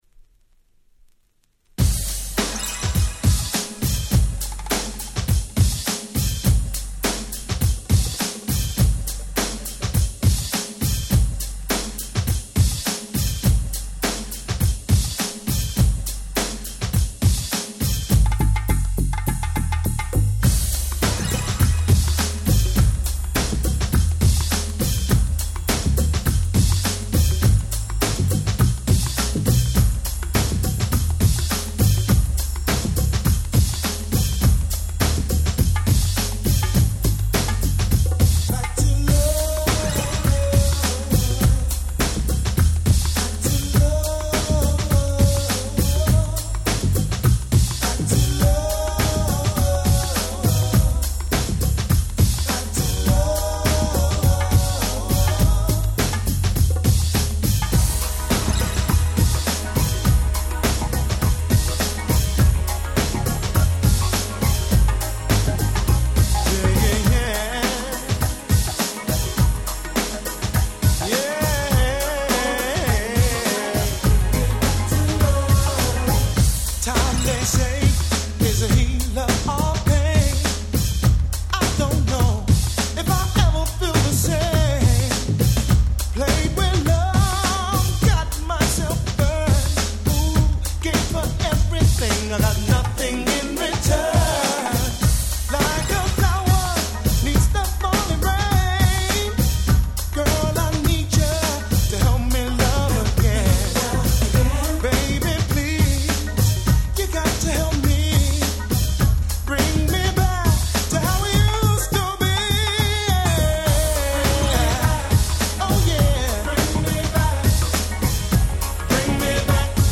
Nice UK R&B !!!